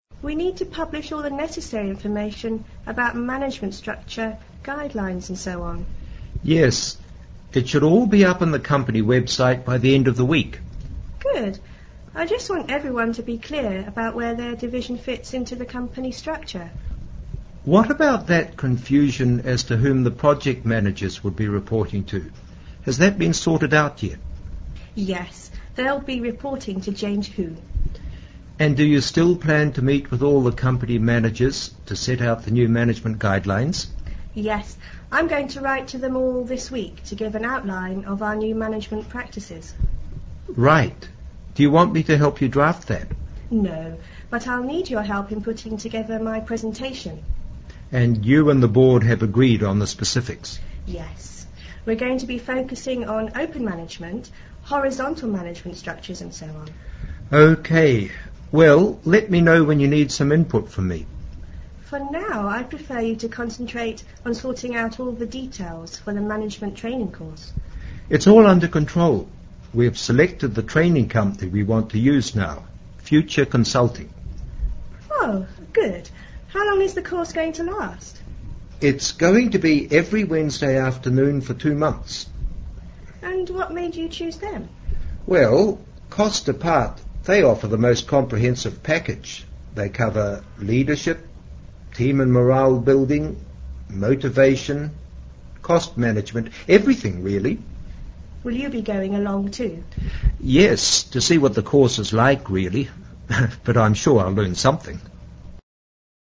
Listen to the dialogue between the Company president and HR manager,